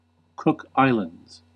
Ääntäminen
Ääntäminen US UK : IPA : /ˈkʊk ˈai.ləndz/ US : IPA : /ˈkʊk ˈai.ləndz/ Haettu sana löytyi näillä lähdekielillä: englanti Käännös Erisnimet 1.